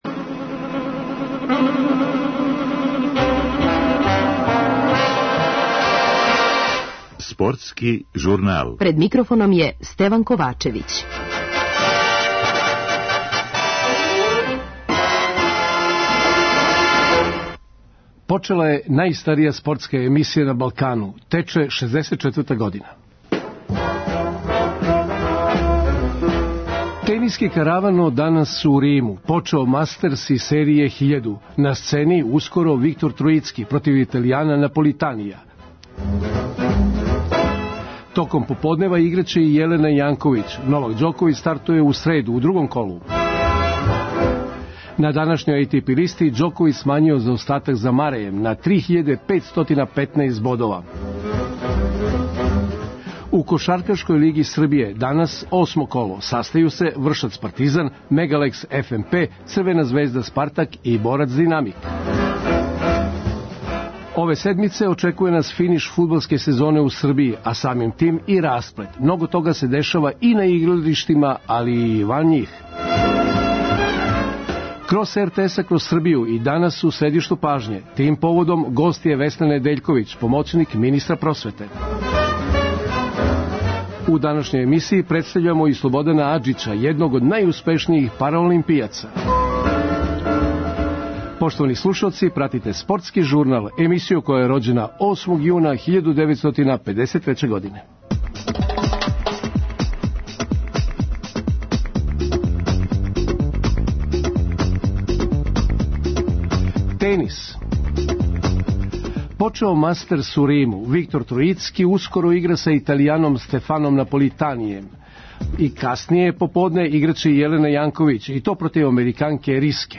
Тим поводом гост емисије је Весна Недељковић, помоћник министра просвете.